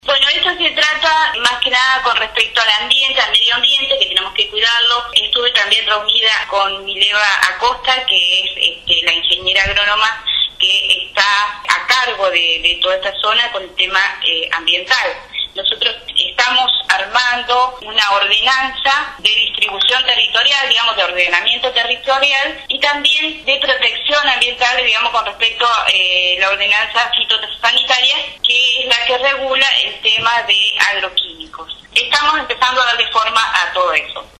La presidenta comunal de San Bernardo, Saida Asan, dio detalles de las últimas novedades referidas a la localidad, destacando la importancia de las distintas iniciativas que están en marcha: construcción de dos viviendas, colocación de carteles nomencladores, puesta en marcha del programa Raíces, acciones para cuidar el medio ambiente, y también brindó un panorama referido a las recientes lluvias.